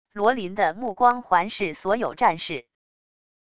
罗林的目光环视所有战士wav音频生成系统WAV Audio Player